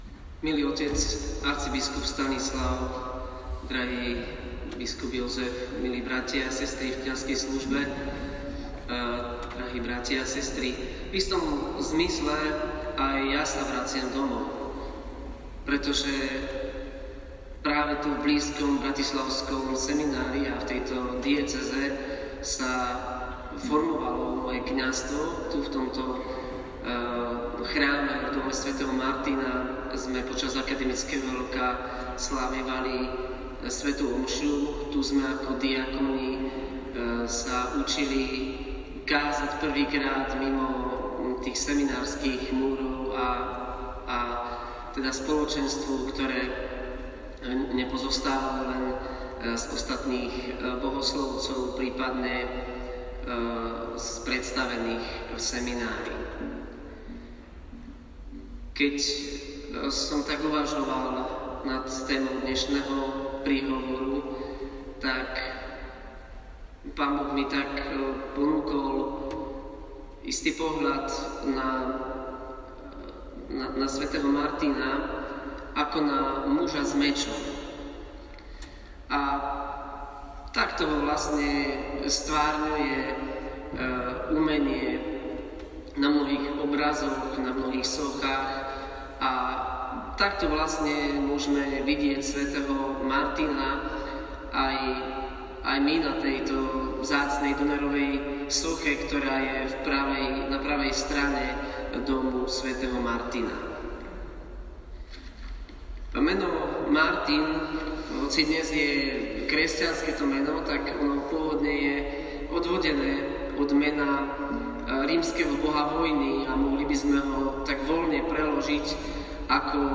Kázeň